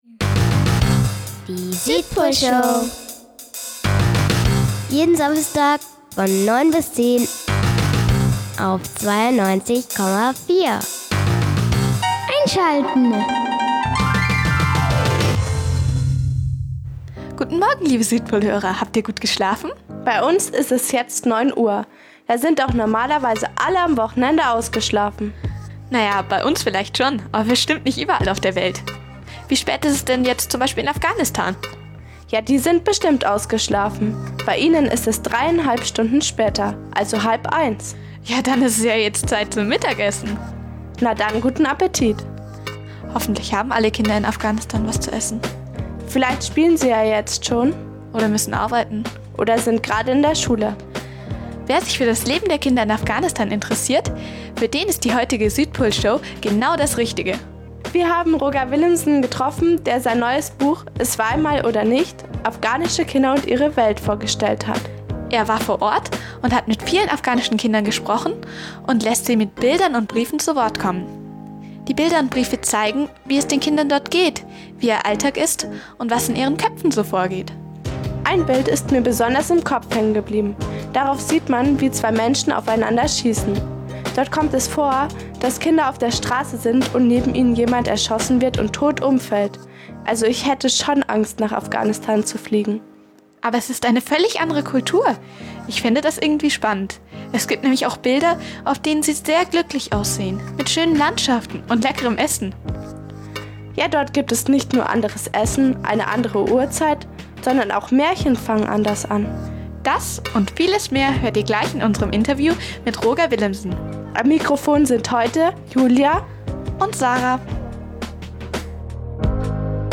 Interview mit Roger Willemsen ~ Südpolshow | Radio Feierwerk 92,4 Podcast
Die Südpolreporter haben die, leider inzwischen verstorbene Interviewer- Legende, Roger Willemsen auf der Bücherschau 2013 in München getroffen und zu seinem Buch "Kinder in Afghanistan" interviewt.